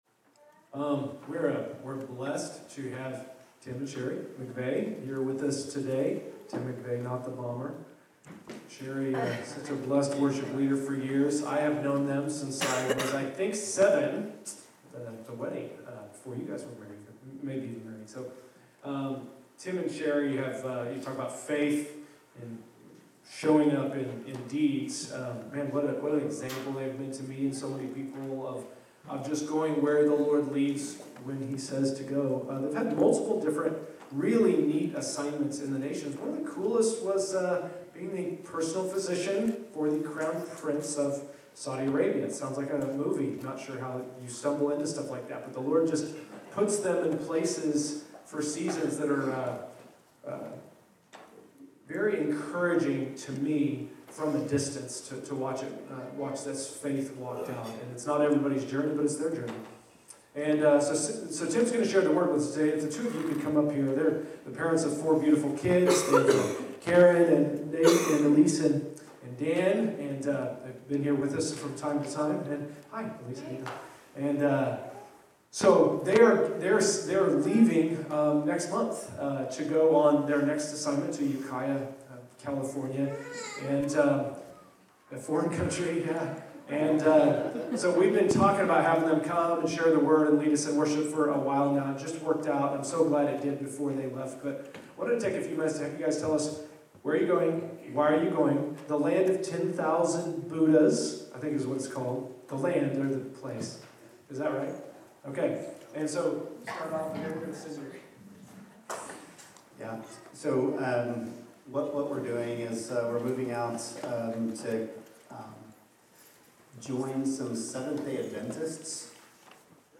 Category: Send Out      |      Location: El Dorado